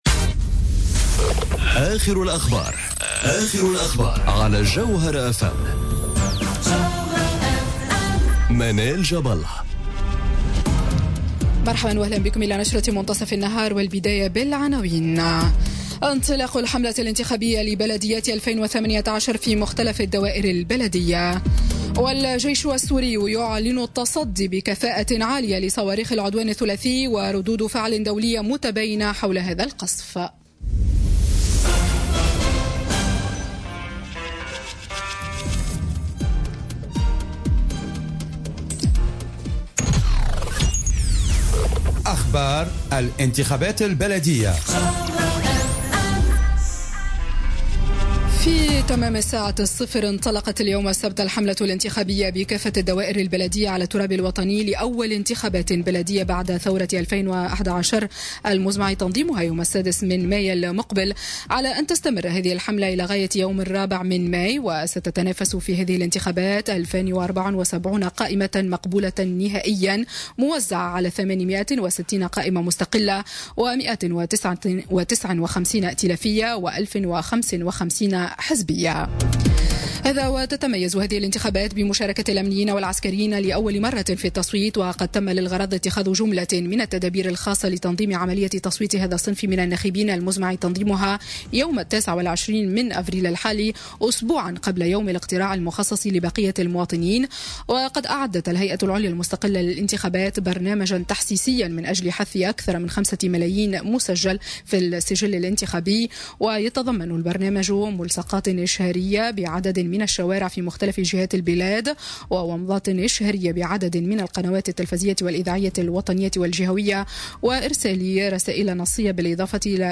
نشرة أخبار منتصف النهار ليوم السبت 14 أفريل 2018